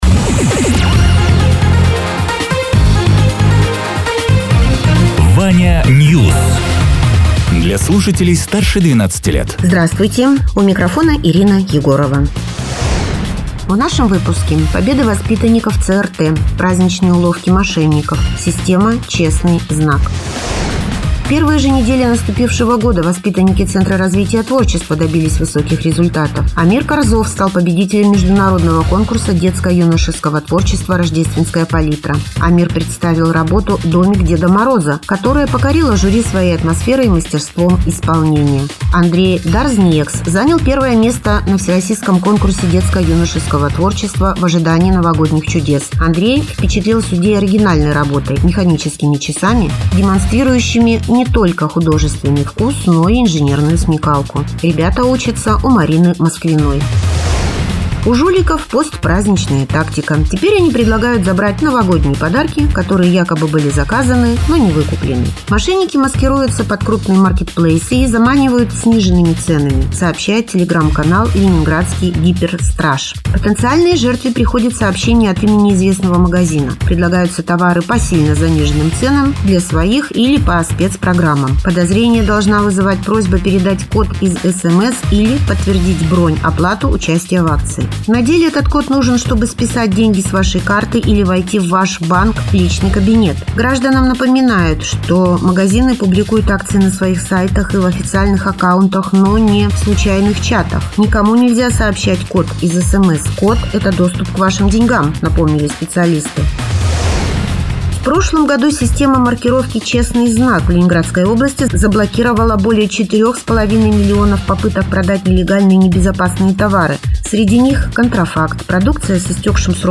Радио ТЕРА 29.01.2026_12.00_Новости_Соснового_Бора